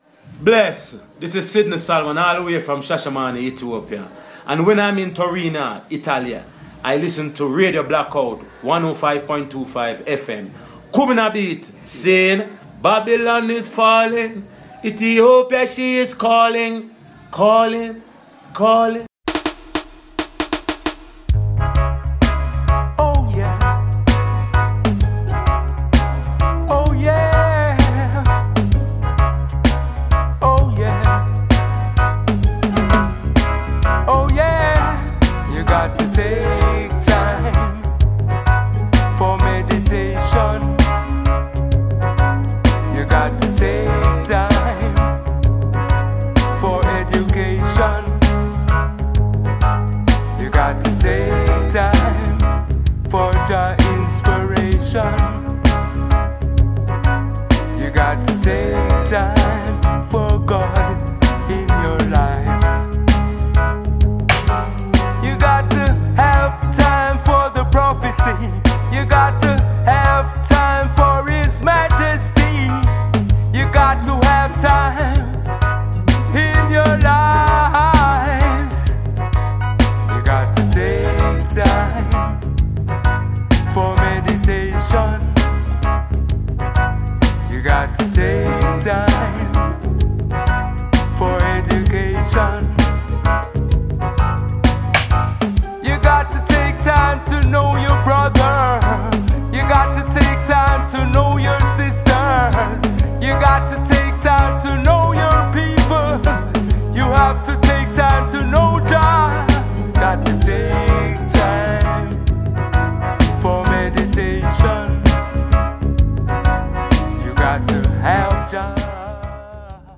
Recorded inna home studio @ Garraf, Catalunya.